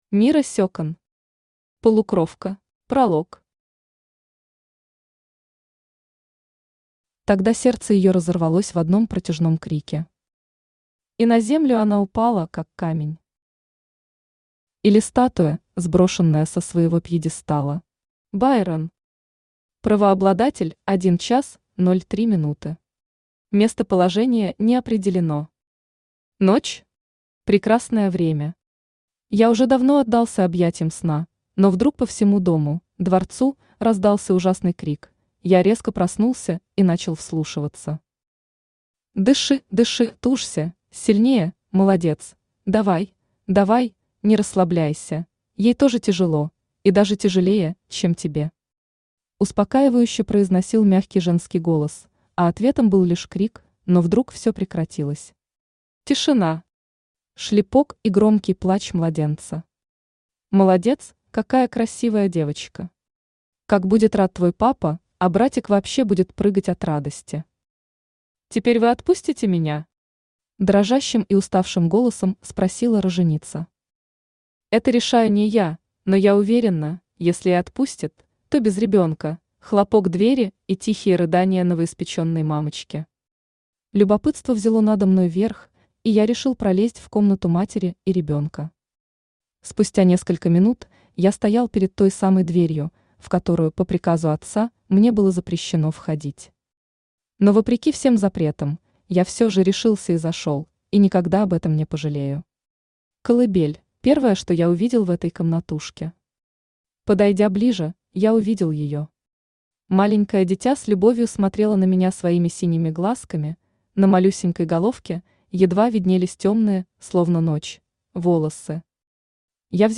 Аудиокнига Полукровка | Библиотека аудиокниг
Aудиокнига Полукровка Автор Мирра Секан Читает аудиокнигу Авточтец ЛитРес. Прослушать и бесплатно скачать фрагмент аудиокниги